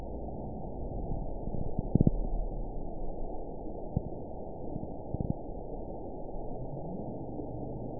event 921995 date 12/24/24 time 23:35:56 GMT (5 months, 4 weeks ago) score 9.13 location TSS-AB04 detected by nrw target species NRW annotations +NRW Spectrogram: Frequency (kHz) vs. Time (s) audio not available .wav